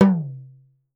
04 TALKING D.wav